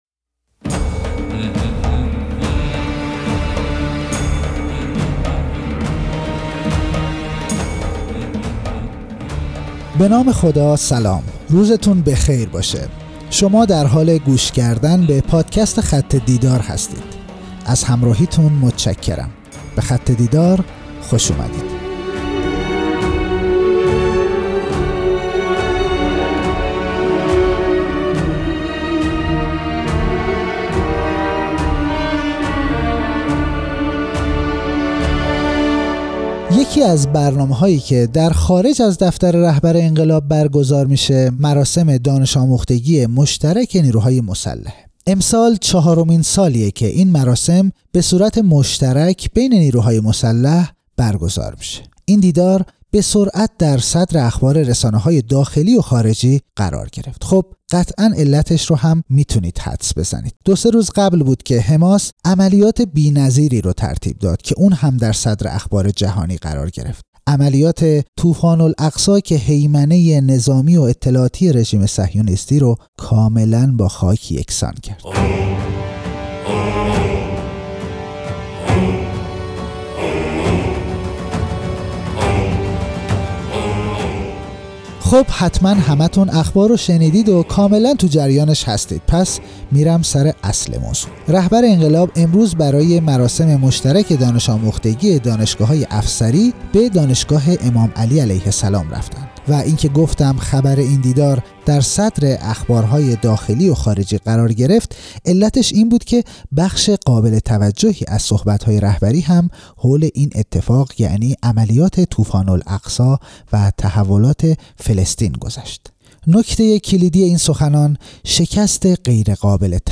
بیانات در مراسم مشترک دانش‌آموختگی دانشجویان نیروهای مسلح